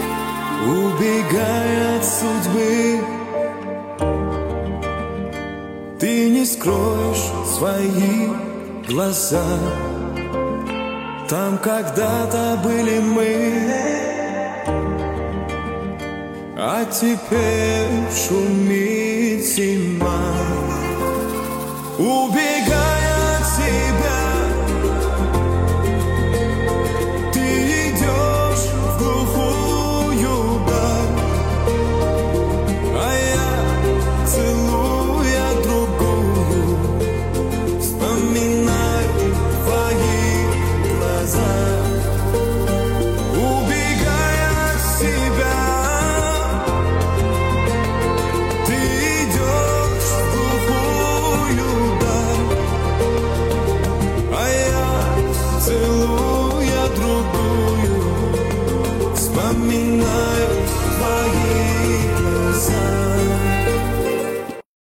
Жанр: Казахские / Узбекские